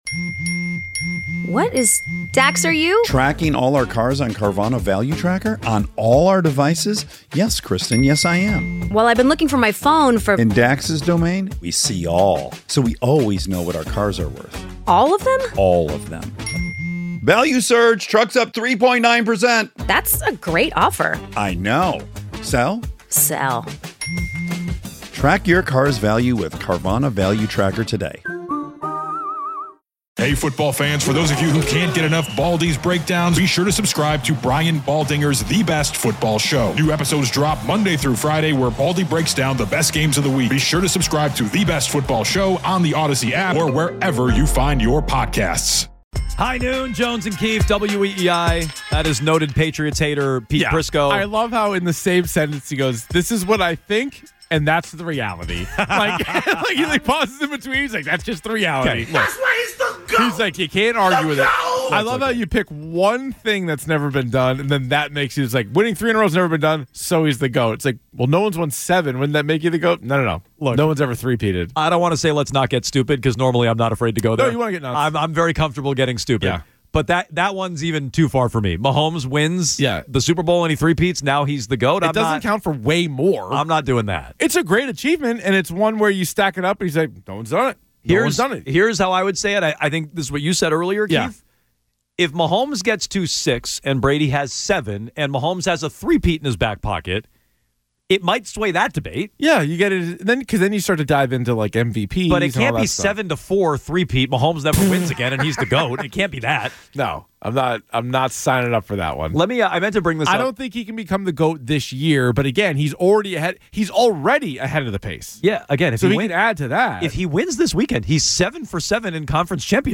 During the third hour of the Jones and Keefe show the guys continue the debate between Patrick Mahomes and Tom Brady. Plenty of angry Patriots fans call in and make their voices heard. Then the Week in Review.